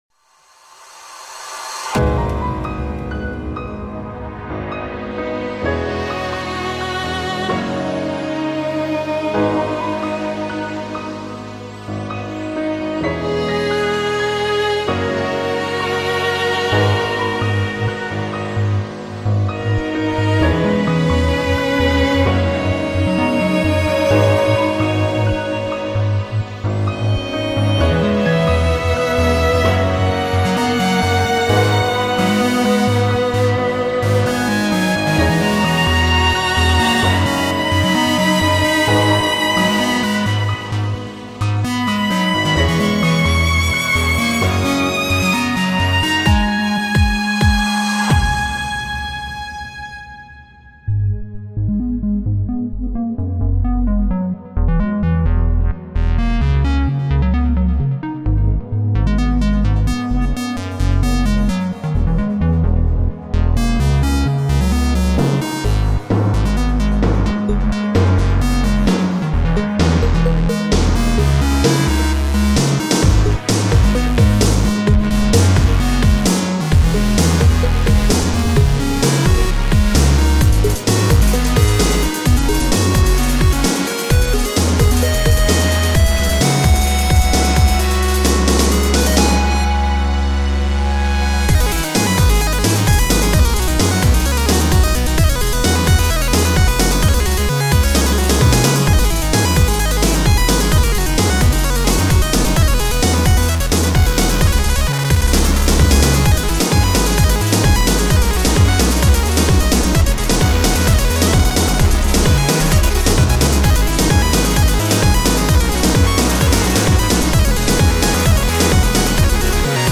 quite a bit edgier, gritty and more energetic